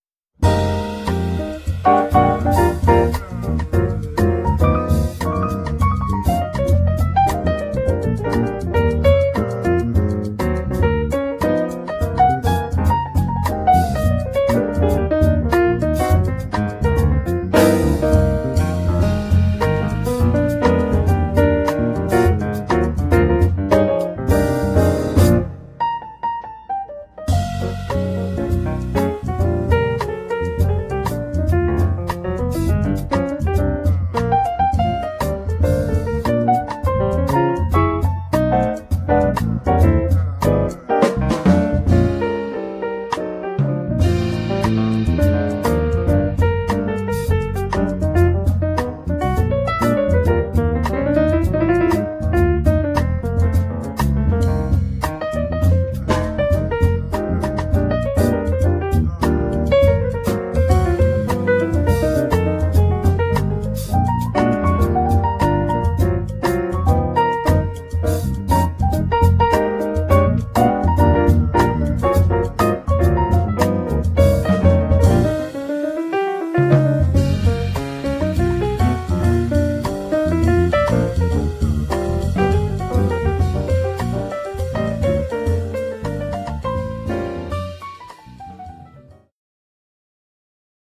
thoughtful, driving and contemporary jazz
piano
vibes
trumpet & flugelhorn
bass
drums